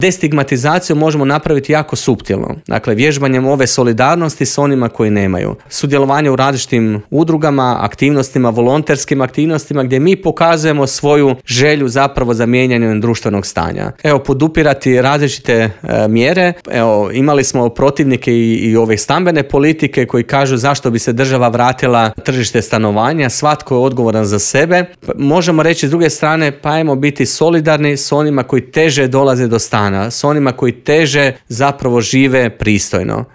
Prate li to socijalne mjere i može li se siromaštvo prevenirati - neke su od teme o kojima smo u Intervjuu Media servisa razgovarali